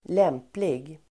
Uttal: [²l'em:plig]